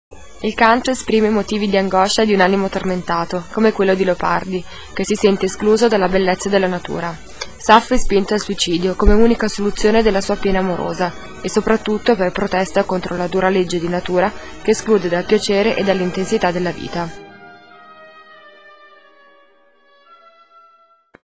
commento sonoro